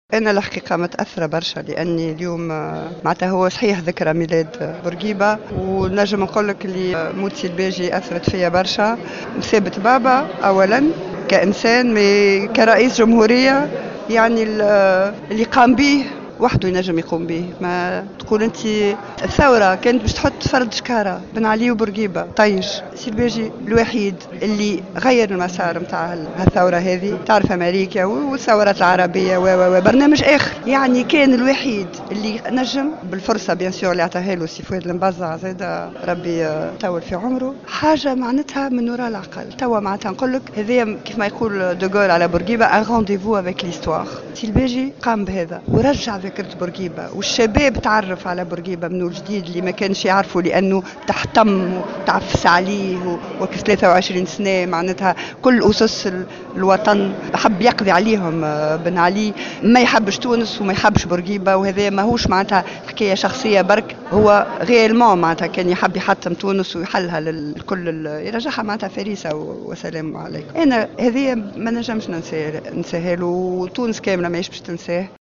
في تصريح للجوهرة أف أم، على هامش ندوة فكرية